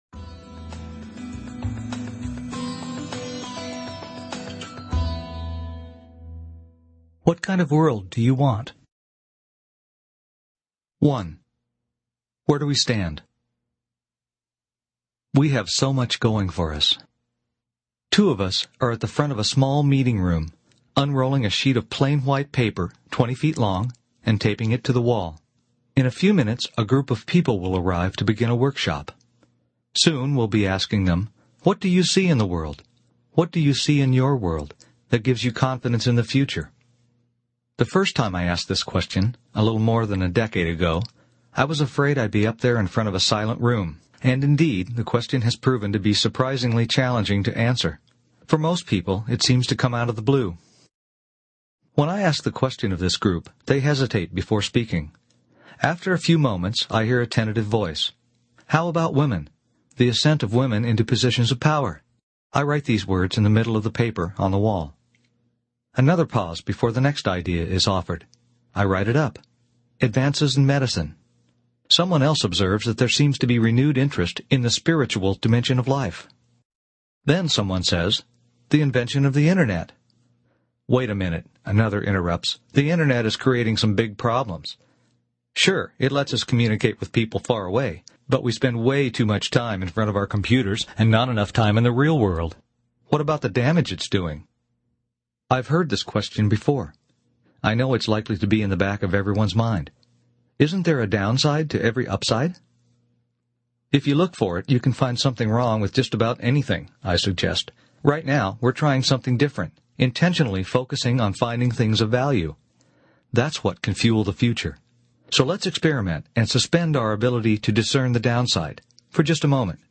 Also available as a professionally recorded audiobook, in two formats: